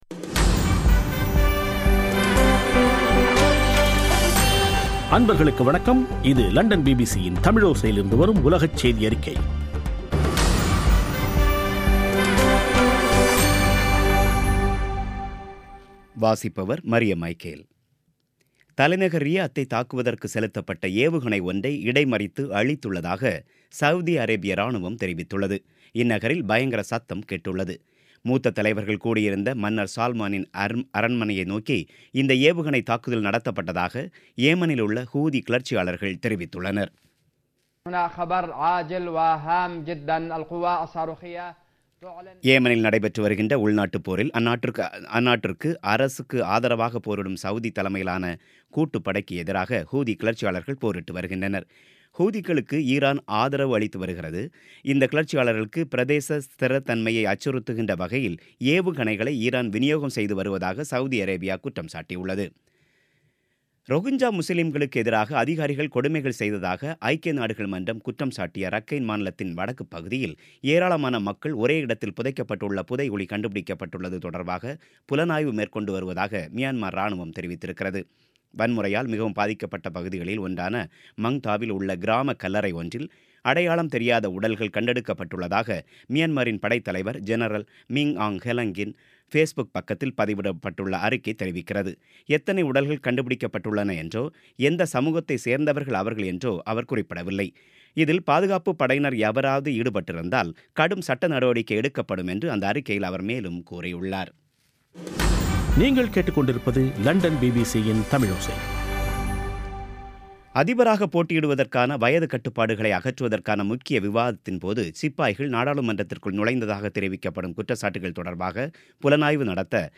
பிபிசி தமிழோசை செய்தியறிக்கை (19/12/2017)